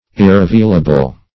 Search Result for " irrevealable" : The Collaborative International Dictionary of English v.0.48: Irrevealable \Ir`re*veal"a*ble\, a. Incapable of being revealed.